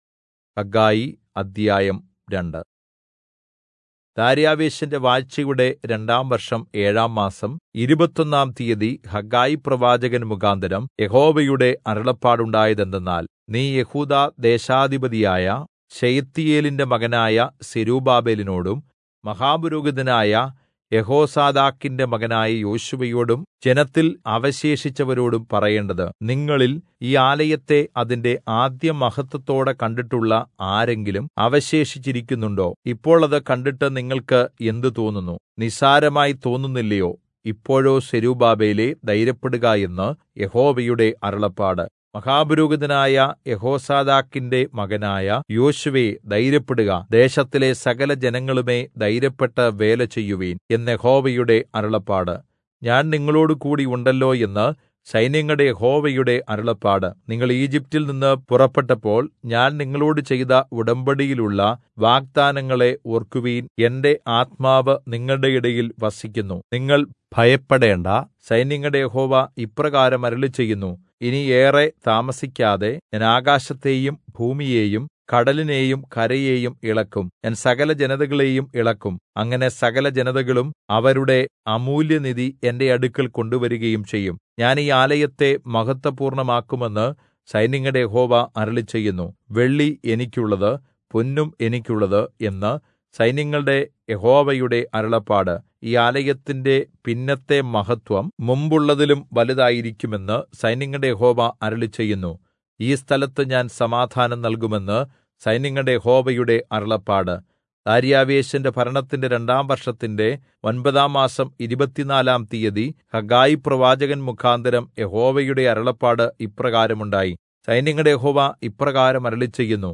Malayalam Audio Bible - Haggai All in Irvml bible version